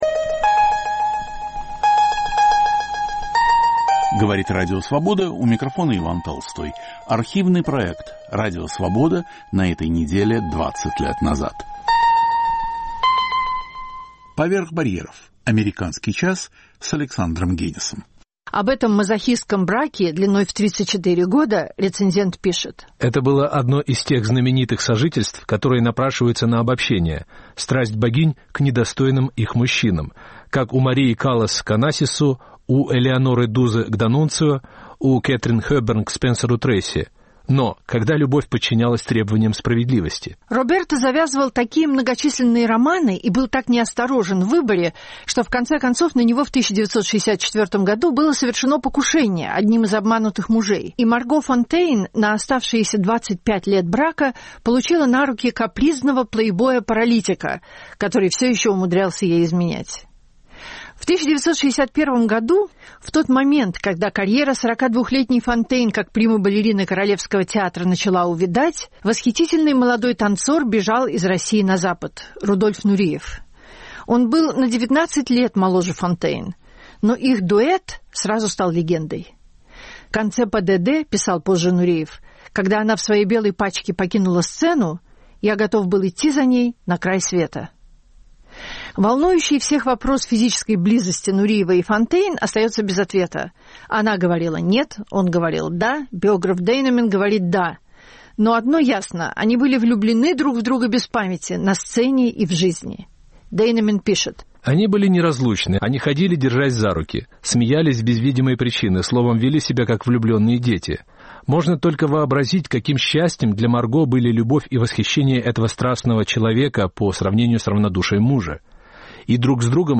Редактор и ведущий Александр Генис.